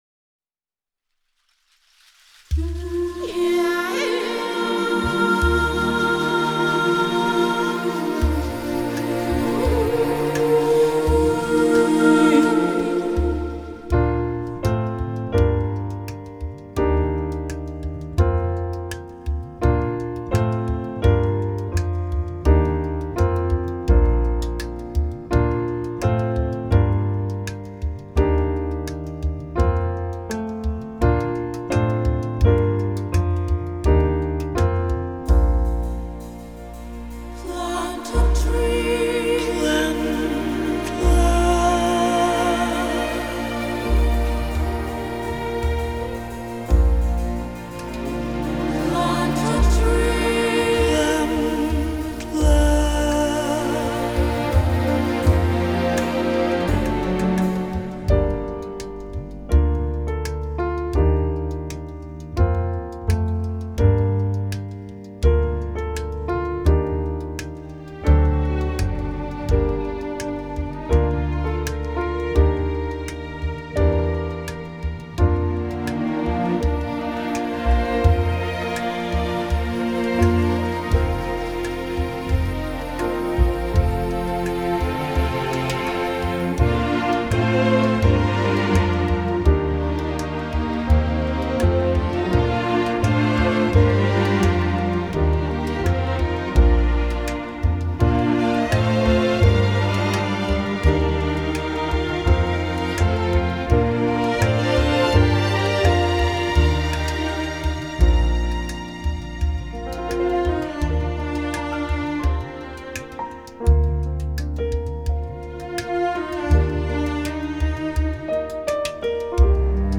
やさしくナチュラルな歌声。
・カラオケ（曲が聴けます）